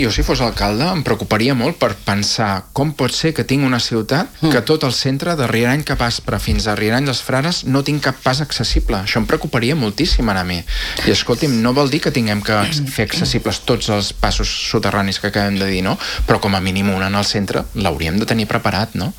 Ho ha dit el portaveu republicà i cap de l’oposició, Xavier Ponsdomènech, en una entrevista al matinal A l’FM i + de Ràdio Calella TV.